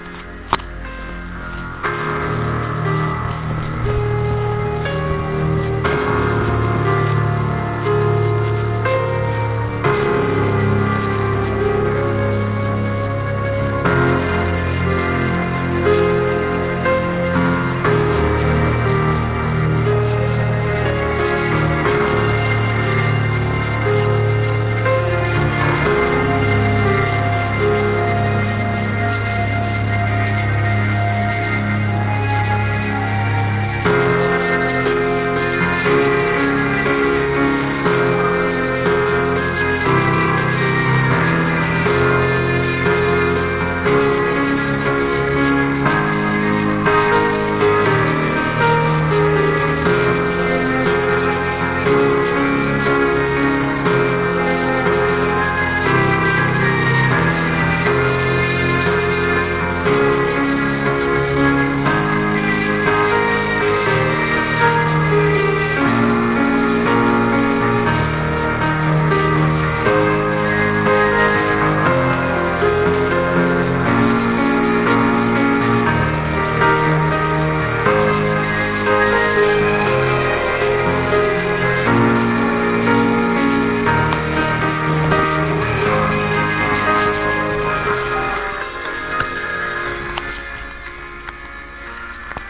Извиняюсь за качество записи через наушники)) Ну очень сильно хочется узнать исполнителя этой мелодии!